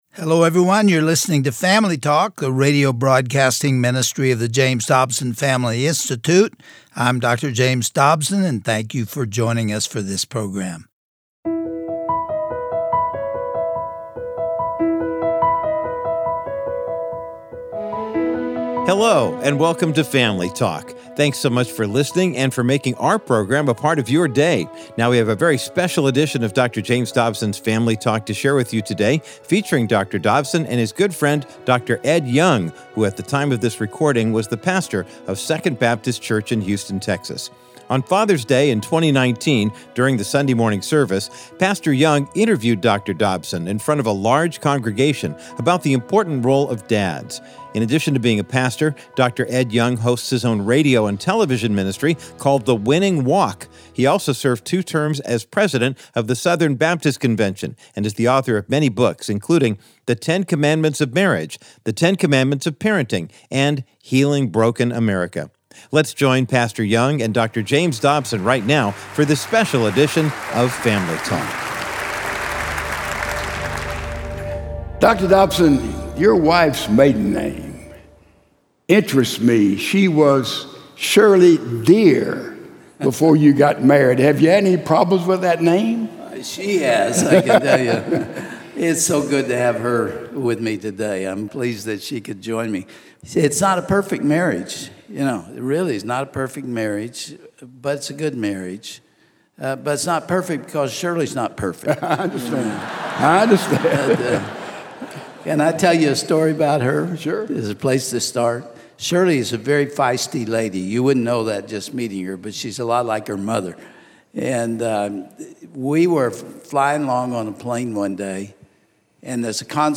“He was my hero!” Those are the words of Dr. James Dobson when describing his own father as he was interviewed by Pastor Ed Young on Father’s Day in 2019.